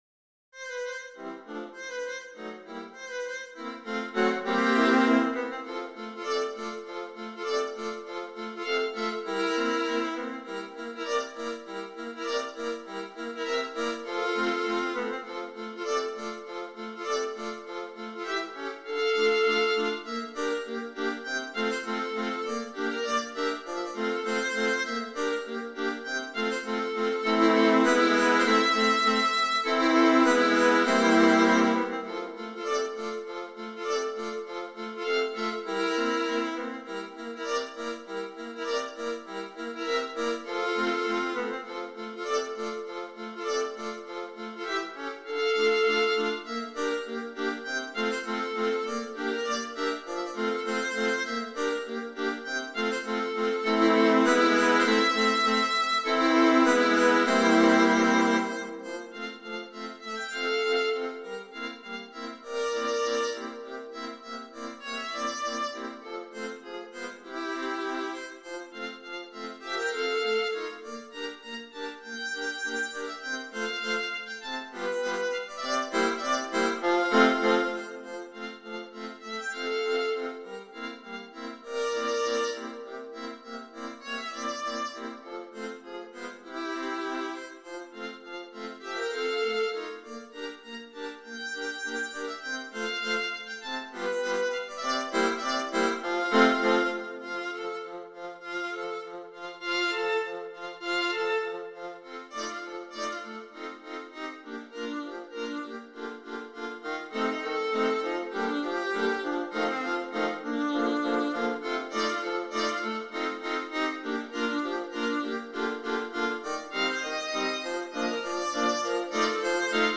viola quartets